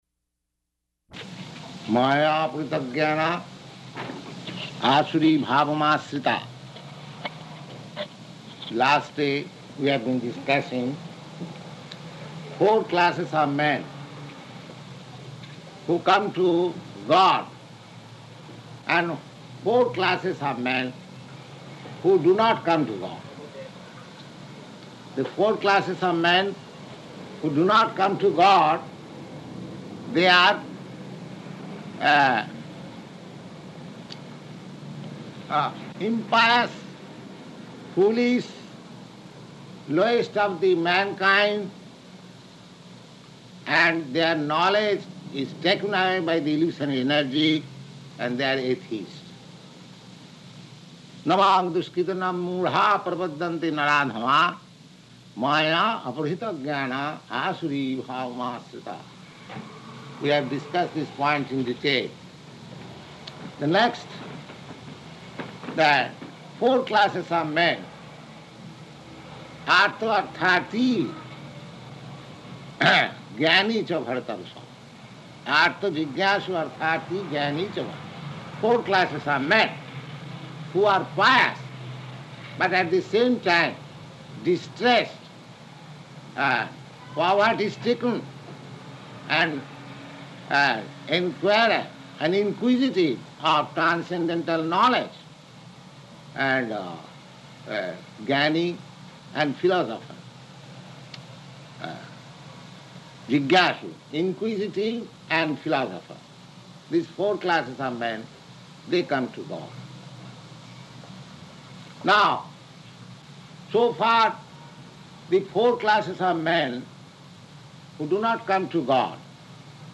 Bhagavad-gītā 7.15–18 --:-- --:-- Type: Bhagavad-gita Dated: October 7th 1966 Location: New York Audio file: 661007BG-NEW_YORK.mp3 Prabhupāda: Māyayāpahṛta-jñānā āsuraṁ bhāvam āśritāḥ. Last day we have been discussing four classes of men who come to God and four classes of men who do not come to God.